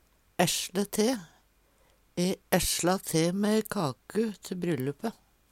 essle te - Numedalsmål (en-US)